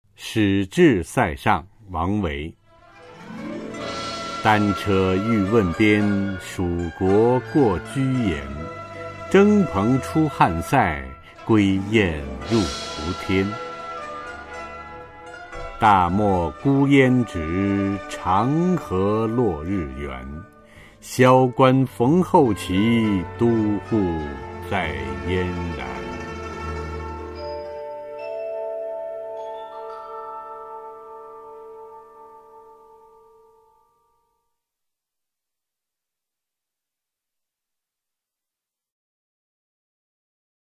[隋唐诗词诵读]王维-使至塞上（男） 配乐诗朗诵